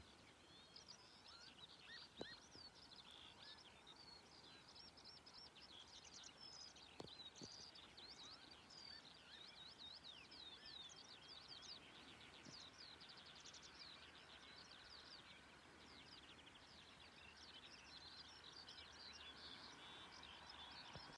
Lauku cīrulis, Alauda arvensis
Vieta - lauksaimniecības zeme, apsēts lauks.